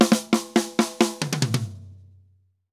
Drum_Break 136-3.wav